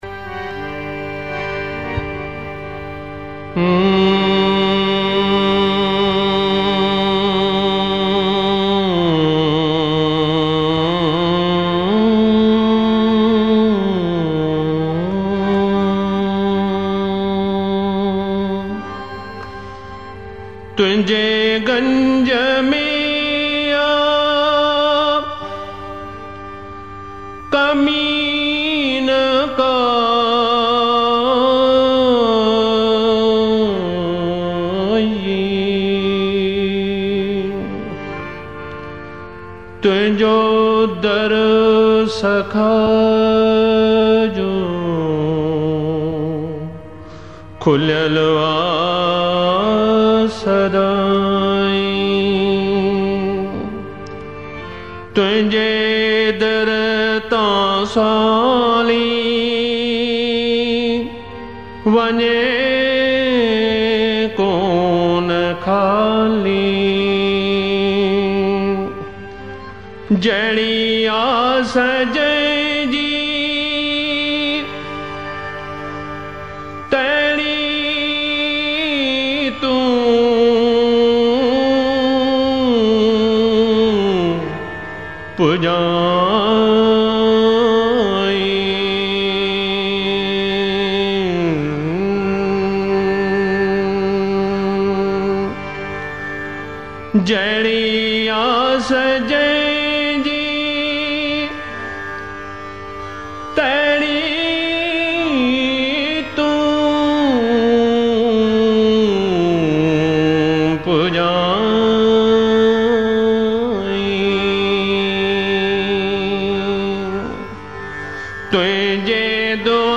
Classical Spiritual Sindhi Songs